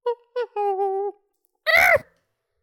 Monkey noise (imitation) 2
Category 🐾 Animals
ape chimp chimpanzee monkey sound effect free sound royalty free Animals